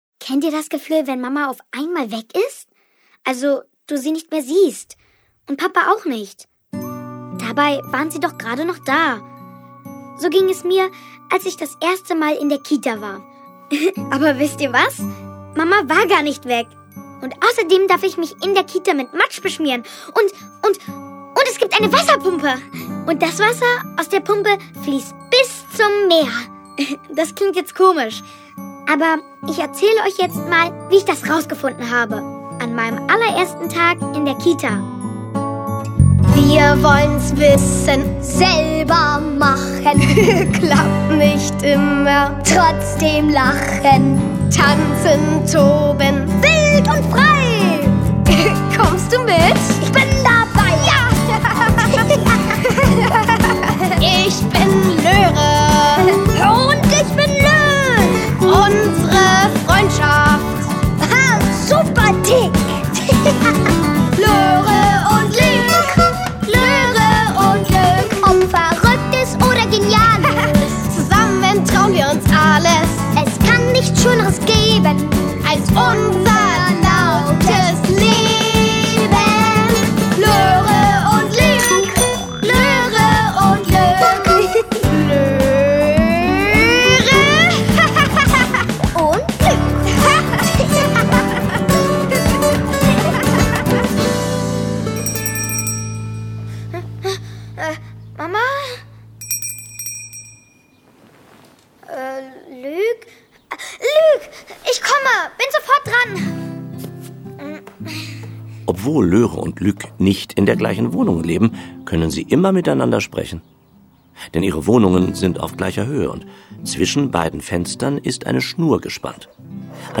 Löre & Luc – Unser lautes Leben (2). Folge 3: Ich komme in die Kita / Folge 4: Ich werde geimpft Hörspiele mit Ilja Richter u.v.a.